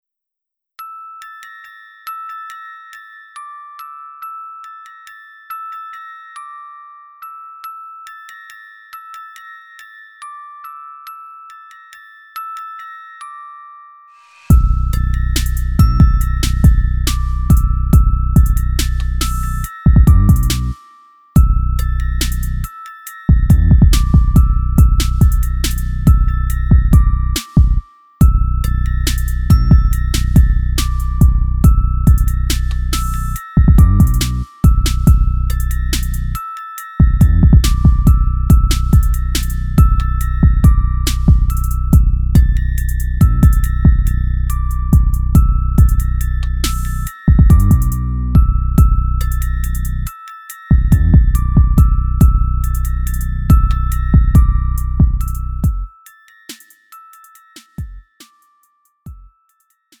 음정 원키 4:11
장르 가요 구분 Lite MR